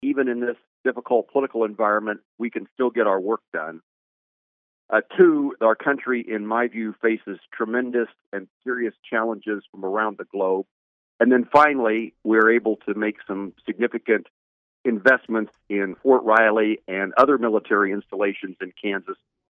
Moran spoke to KMAN about the package and spoke about the importance of getting the bill passed before the end of the year.